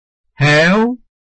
拼音查詢：【饒平腔】heu ~請點選不同聲調拼音聽聽看!(例字漢字部分屬參考性質)